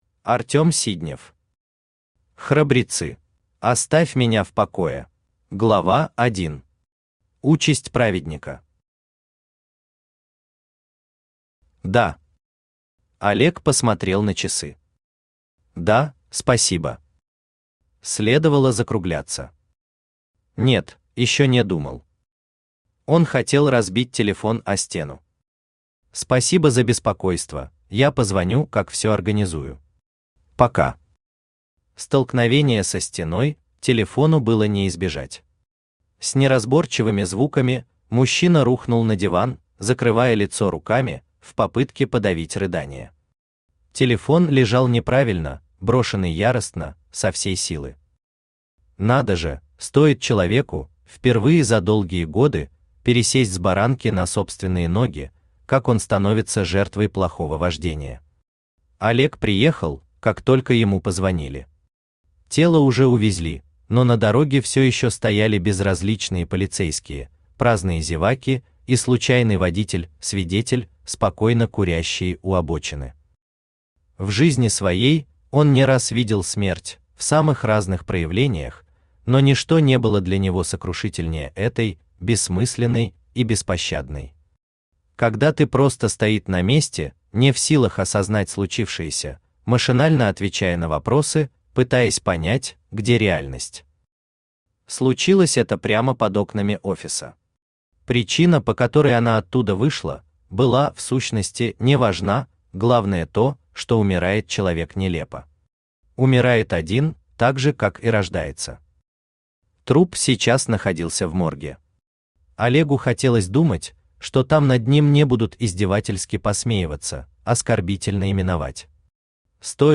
Аудиокнига Храбрецы | Библиотека аудиокниг
Aудиокнига Храбрецы Автор Артём Сиднев Читает аудиокнигу Авточтец ЛитРес.